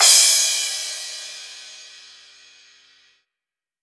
Urban Cymbal 01.wav